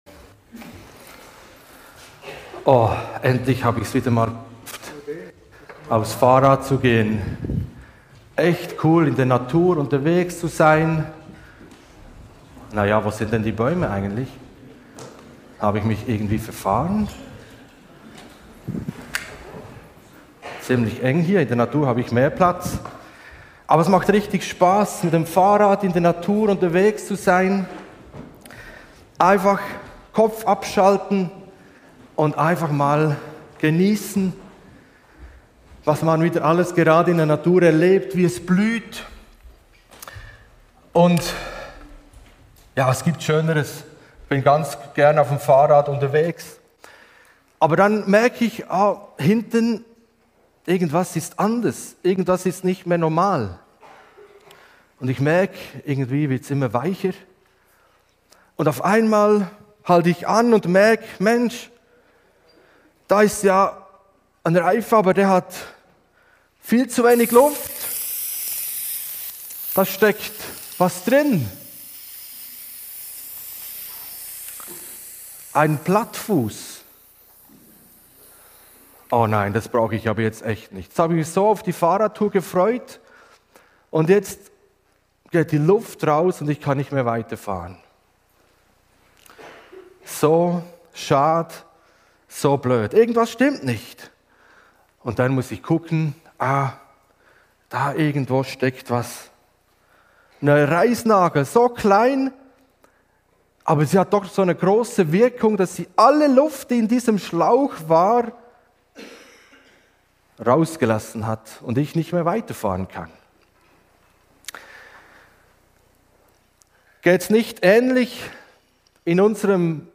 Deshalb wünschen wir dir, dass du mit diesen Predigten Gott erlebst.